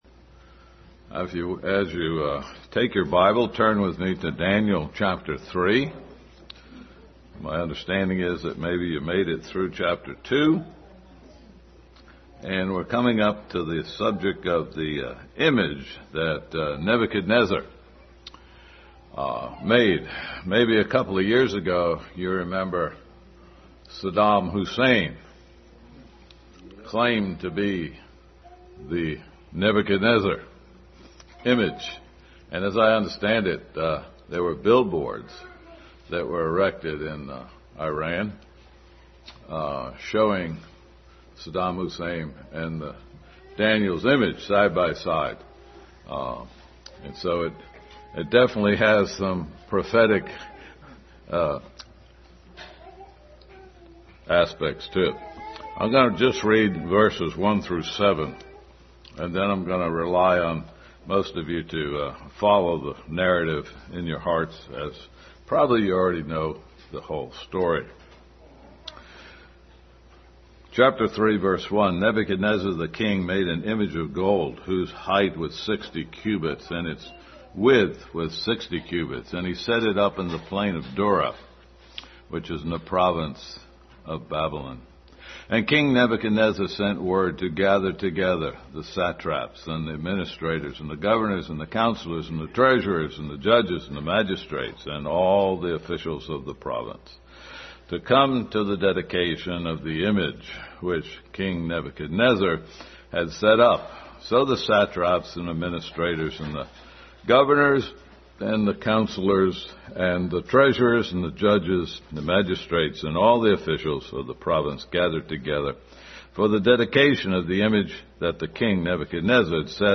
Daniel 3:1-7 Passage: Daniel 3:1-7, 8-12, 13, 16-18, 22-26, Colossians 3:16, Hebrews 5:6, Romans 8:28, Psalm 66:12 Service Type: Family Bible Hour